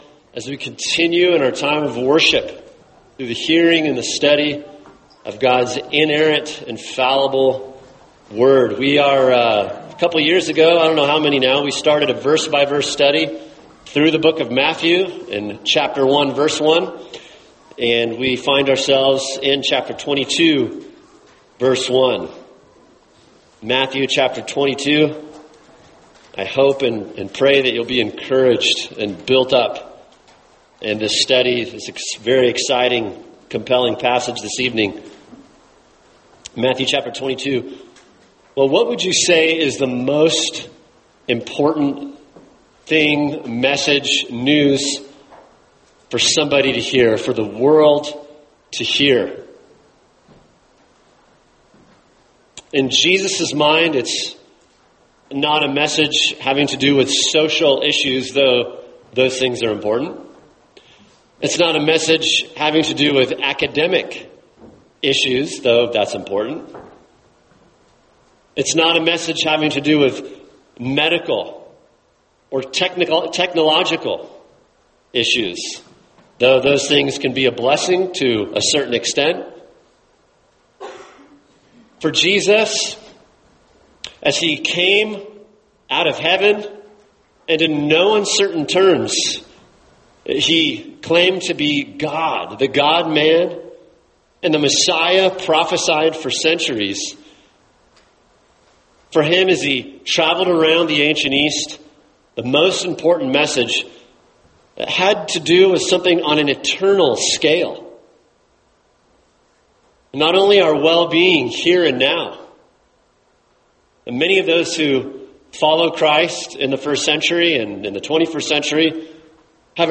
[sermon] Matthew 22:1-14 An Invitation to the Royal Banquet | Cornerstone Church - Jackson Hole